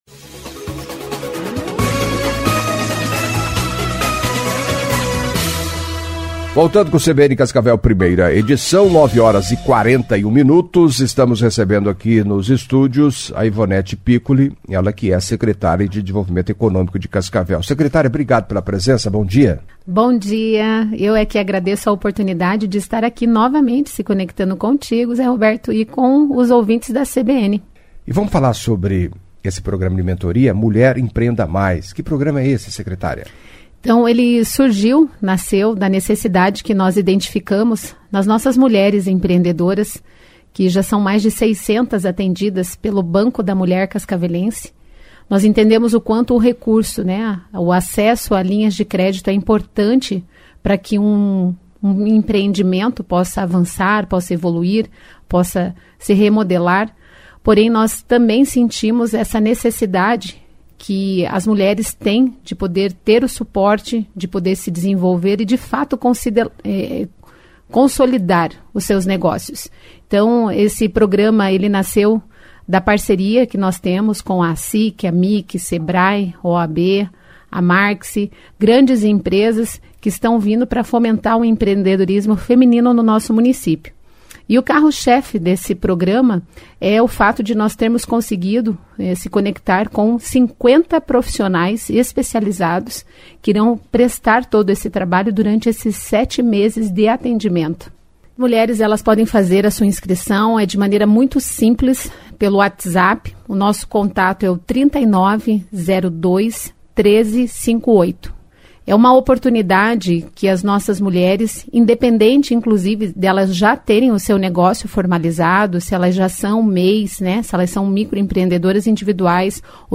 Em entrevista à CBN nesta terça-feira (16), a secretária de Desenvolvimento Econômico de Cascavel, Hivonete Piccoli, reforçou o convite do prefeito Paranhos e do vice Renato Silva para o lançamento do programa de mentoria "Mulher: Empreenda Mais", marcado para quinta-feira (18), no Teatro Emir Sfair, às 19h. Piccoli destacou o empreendedorismo feminino em Cascavel, que tem sido destaque no Paraná e no Brasil, acompanhe.